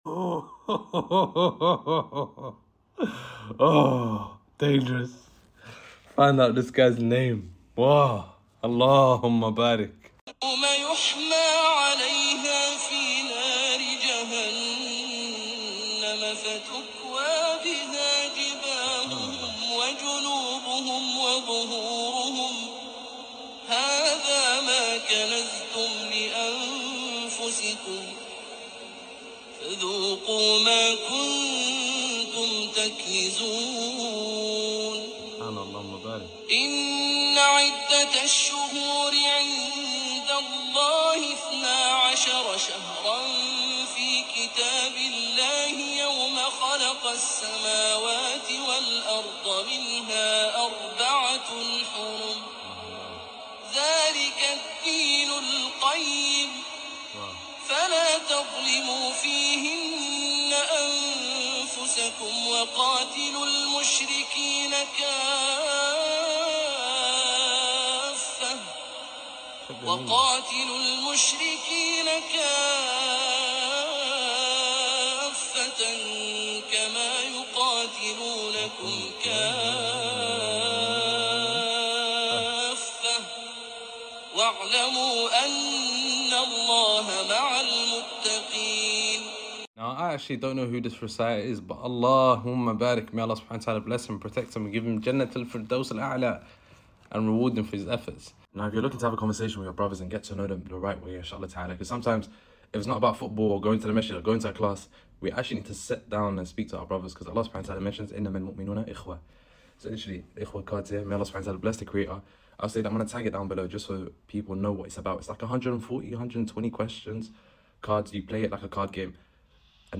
This recitation is beautiful and sound effects free download